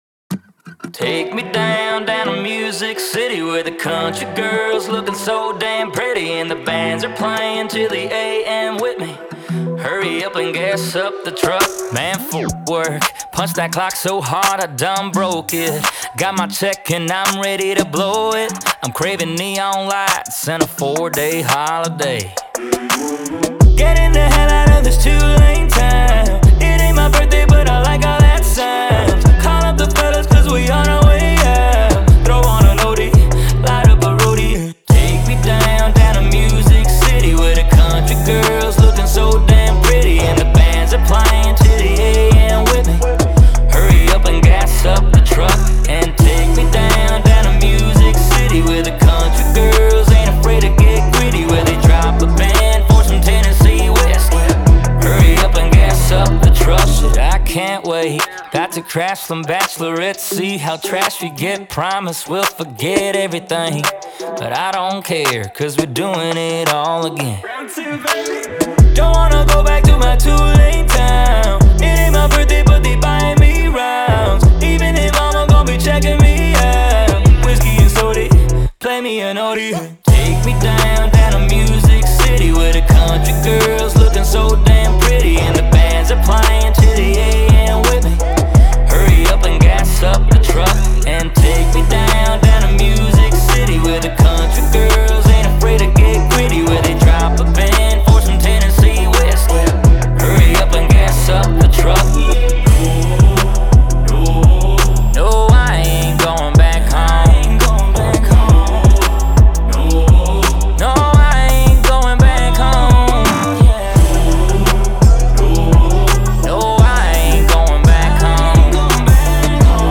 The "Welcome to Nashville" anthem.